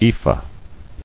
[e·phah]